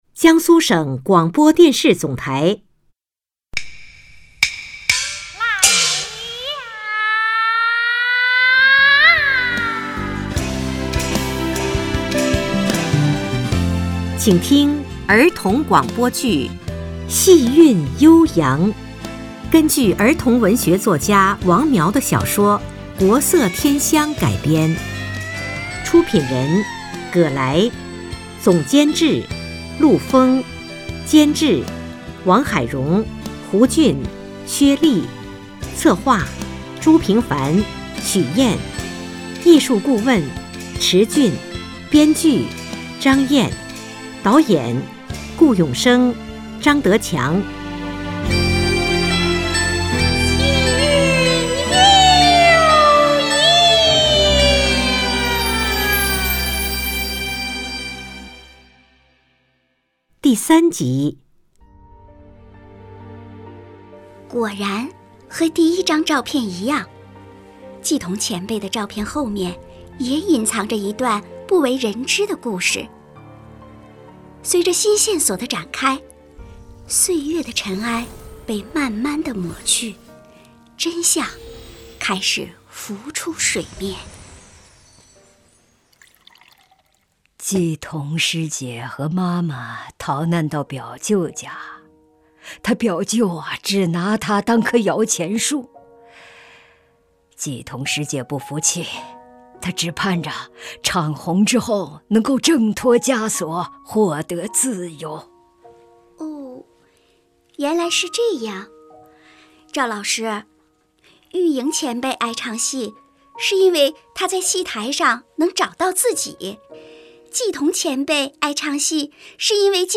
广播类型：儿童剧